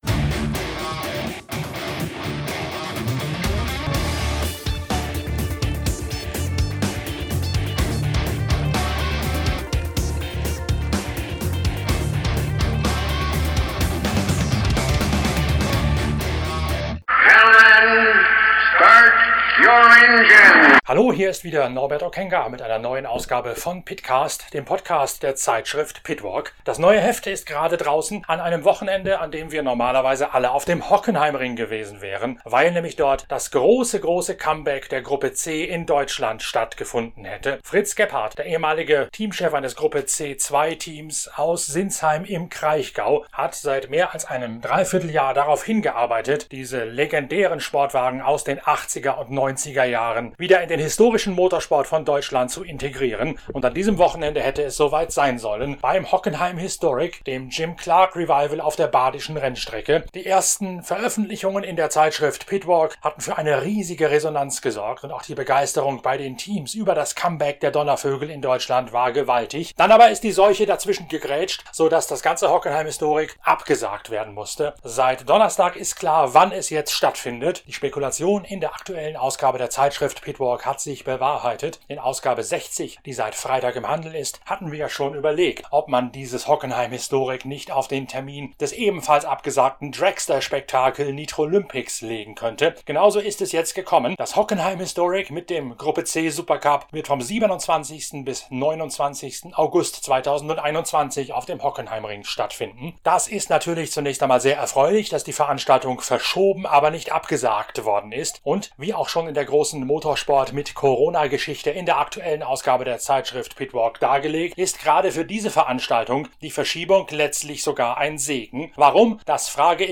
In dem Interview kommen noch mehr spannende Aspekte und Zusammenhänge zu den Auswirkungen von Corona auf den deutschen Motorsport und die Rennveranstalter zur Sprache als bislang bekannt waren.